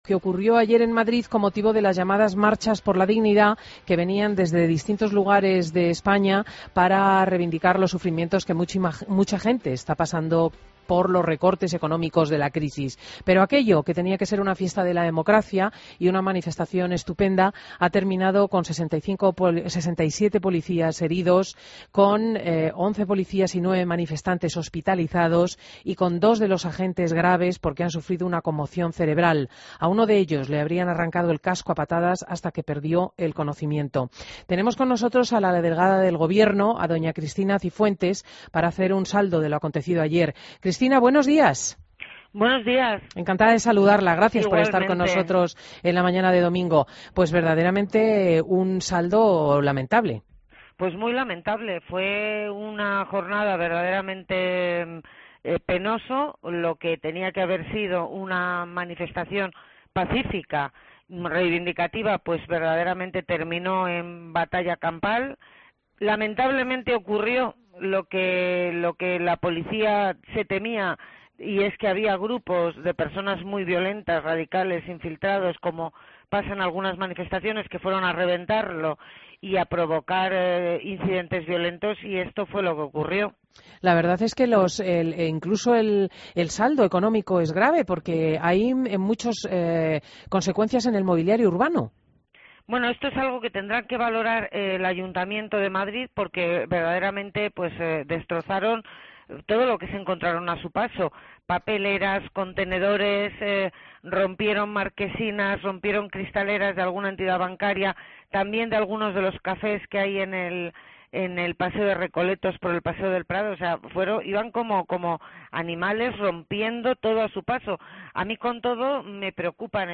Entrevista a Cristina Cifuentes en Fin de Semana COPE